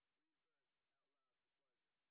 sp04_white_snr10.wav